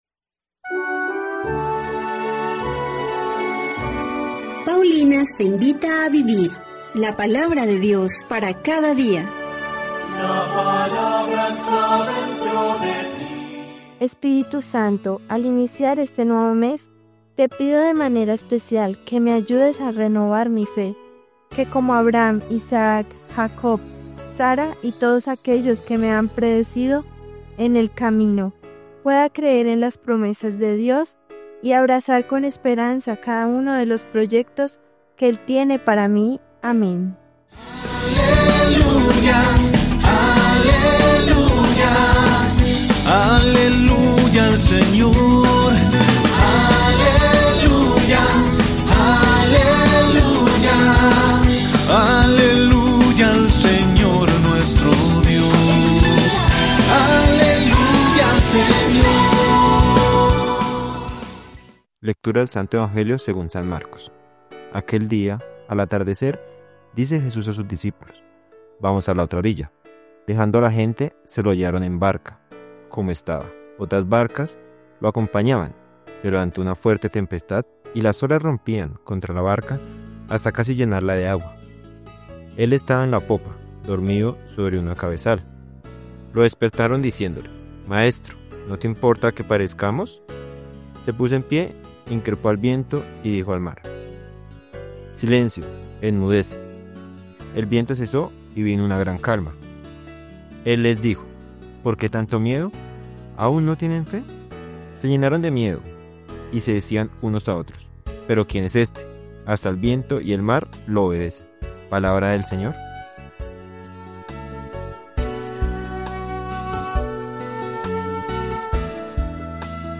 Lectura del Primer libro de los Reyes 2, 1-4. 10-12